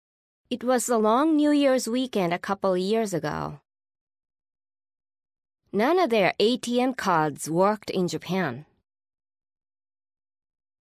PRONUNCIATION: Listening for weak words